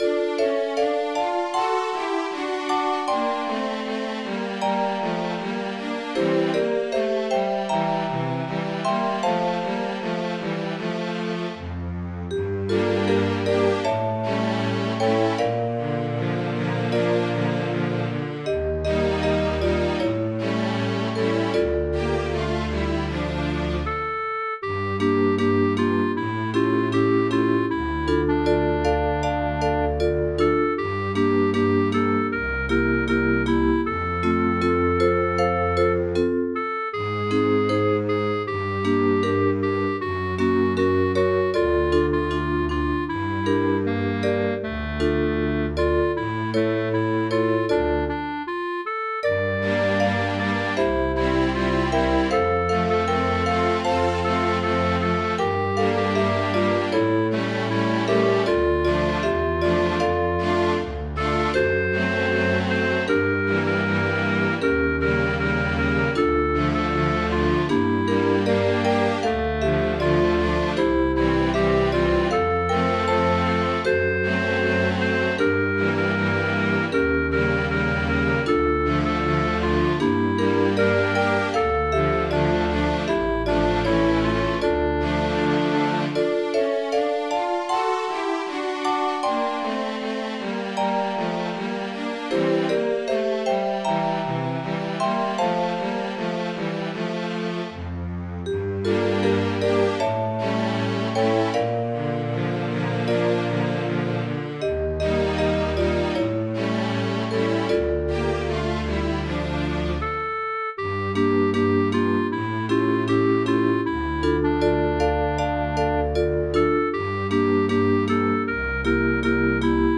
Вирішив написати пісню, можливо це можна вважати романсом.
Тут виставляю оркестровий варіант, а слідом виставлю з фортепіано.